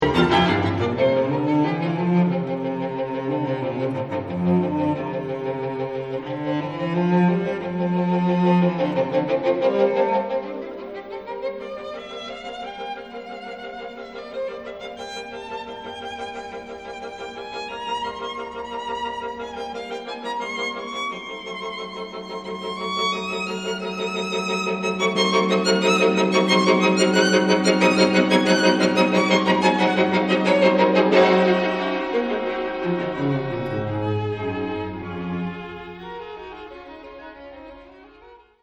muziektheorieanalyse klassieke stukken  > Beethoven: strijkkwartet in F gr.t.  op. 59 nr.1